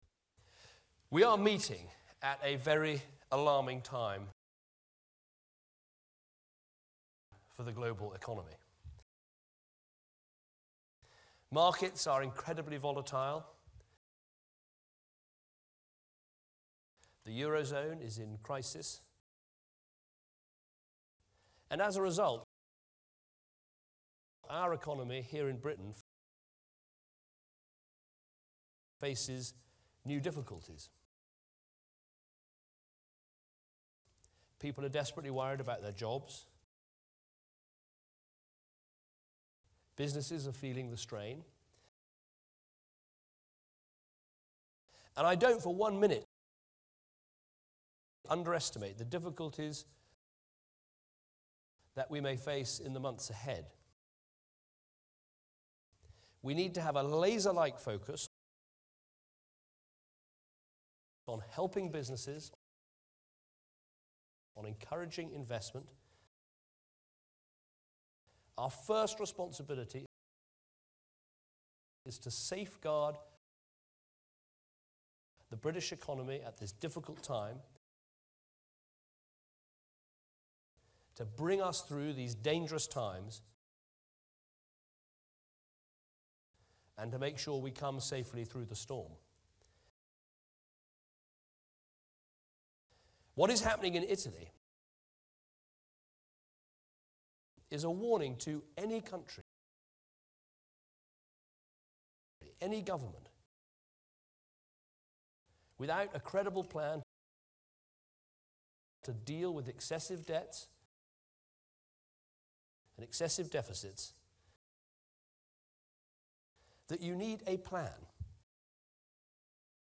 Vous allez maintenez entendre ce même article en 'lecture éclatée', c'est à dire découpée en membres de phrases qui font unité de sens.
Après chaque membre de phrase (et donc à la fin de chaque ligne), il y aura un silence de quelques secondes.
David Cameron, speech on exporting and growth,